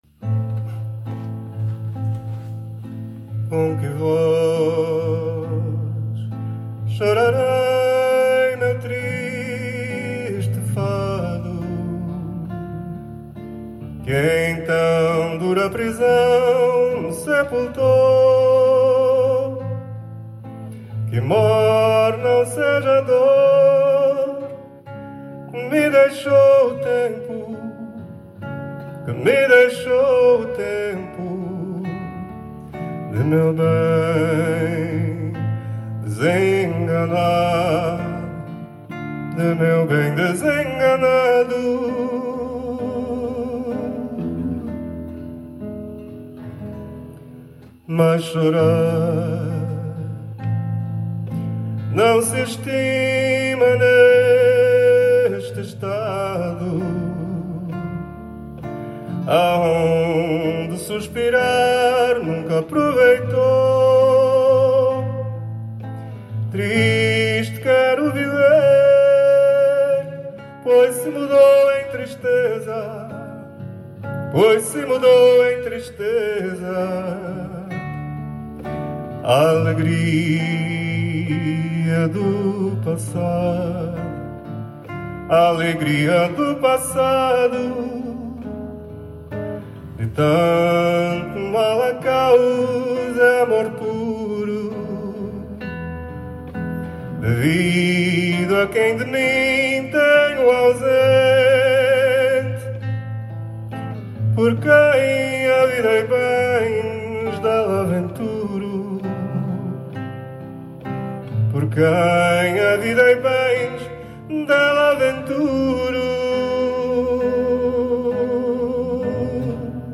Hier befindet sich eine Auswahl unseres Repertoires mit Sheets und einigen Audio-Aufnahmen der Stücke, die in den Proben gemacht wurden.